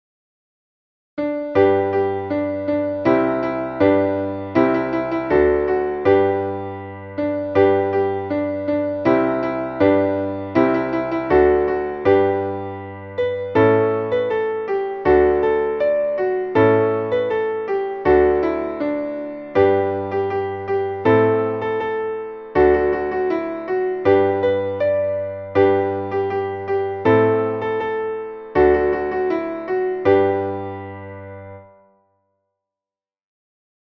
Traditionelles Kinderlied